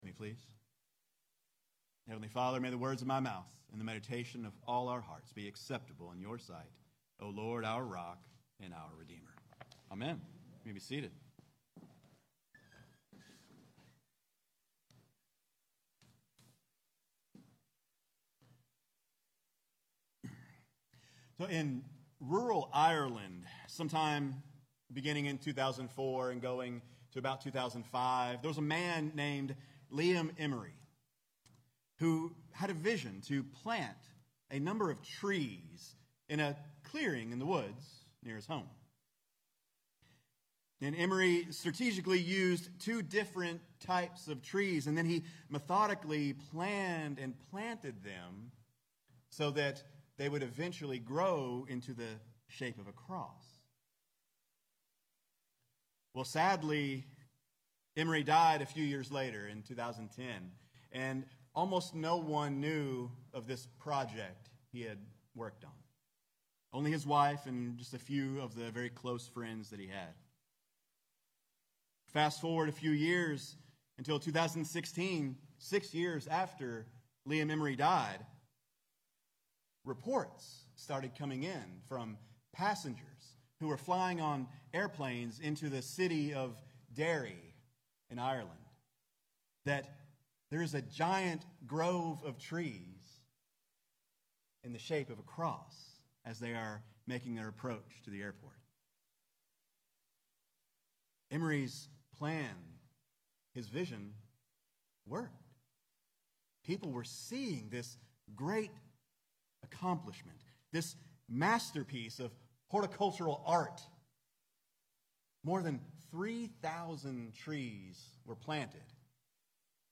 Sermons | St. John's Parish Church